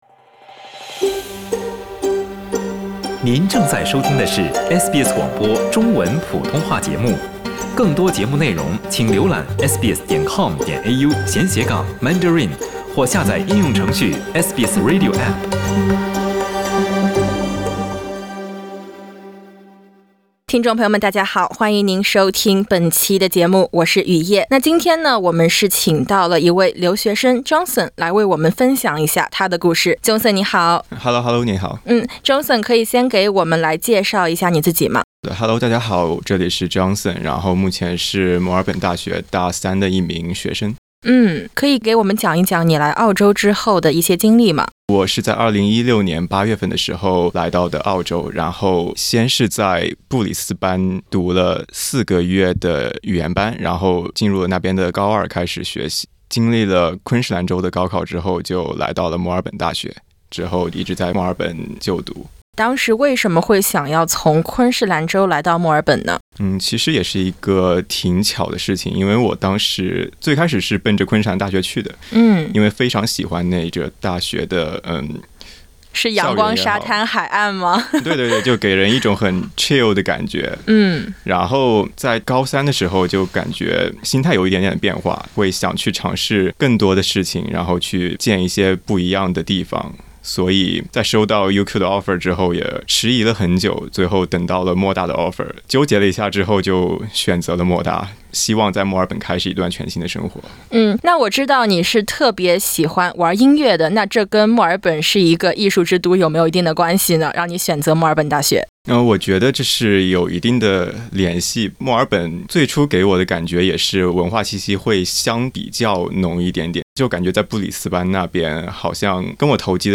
欢迎点击封面音频，收听完整采访。